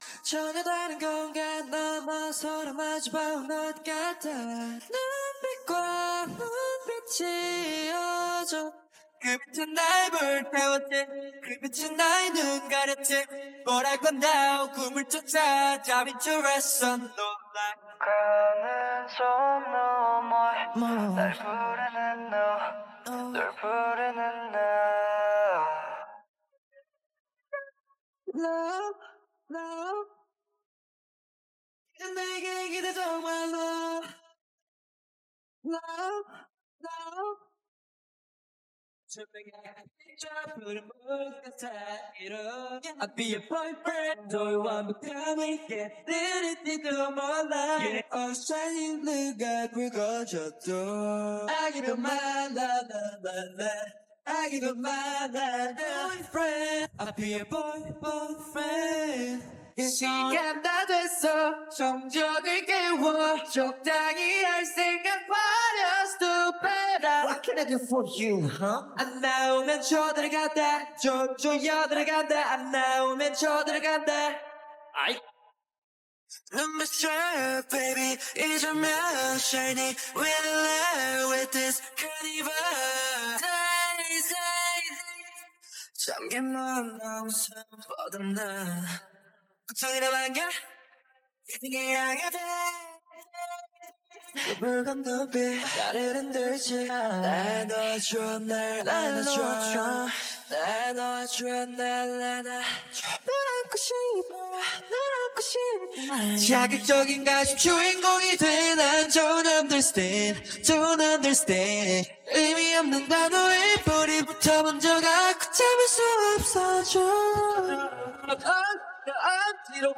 sample 1-D-77bpm-440hz (Vocals) (Mel-RoFormer Karaoke).mp3